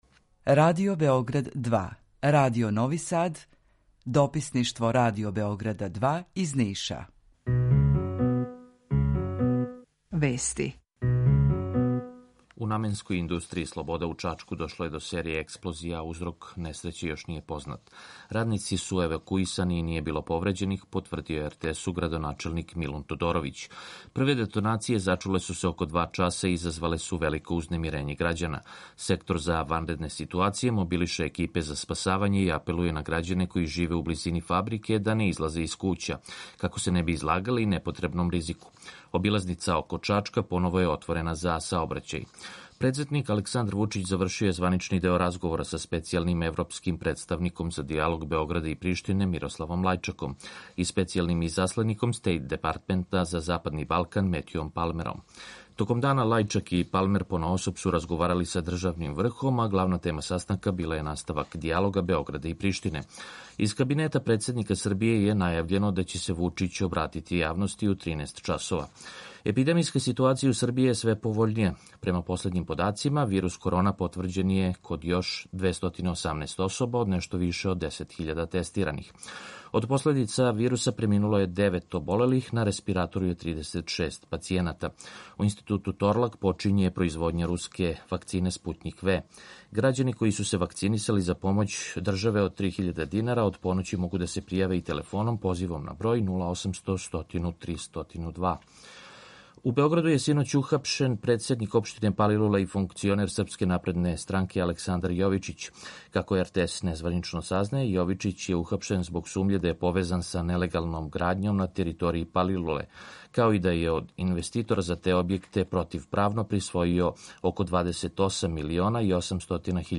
Укључење из Бањалуке
Јутарњи програм из три студија
У два сата, ту је и добра музика, другачија у односу на остале радио-станице.